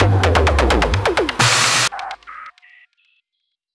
VTDS2 Song Kit 21 Male Out Of My Mind FX Fill.wav